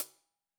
Index of /musicradar/Hi Hats/Sabian B8
CYCdh_Sab_ClHat-01.wav